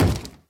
mob / zombie / wood4.ogg
wood4.ogg